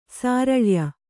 ♪ sāraḷya